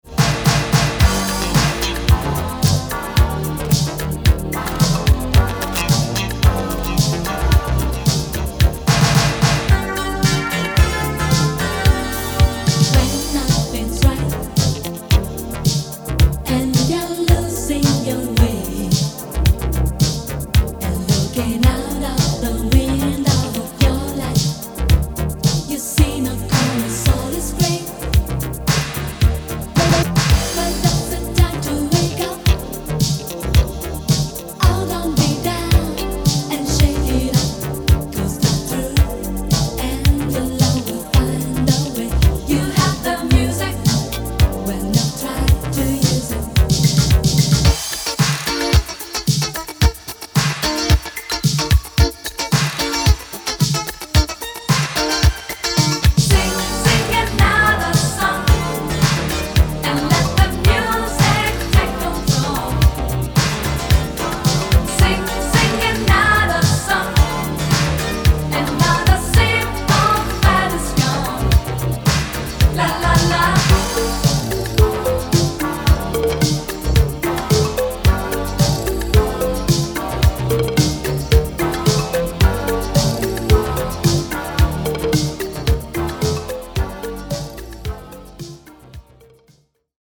Italo Disco